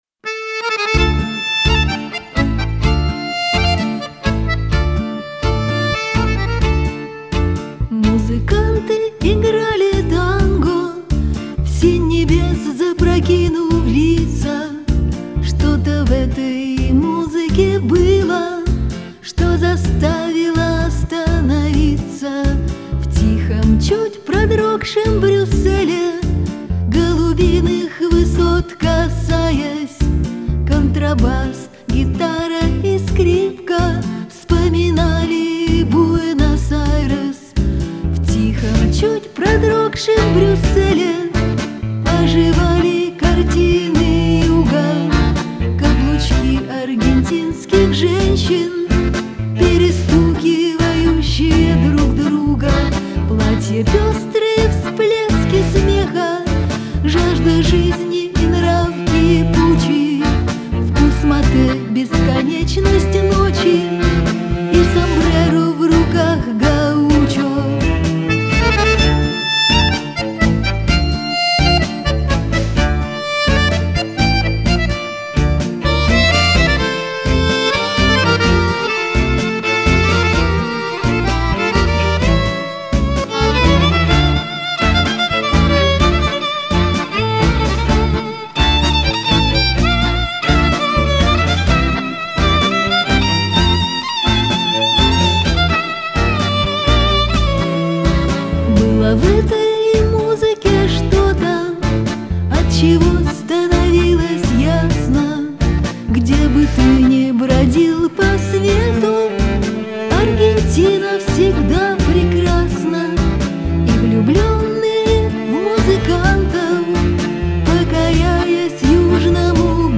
играющая в стиле "Сенти-Ментальный рок".
гитары, клавишные, перкуссия, сэмплы
скрипка
аккордеон
виолончель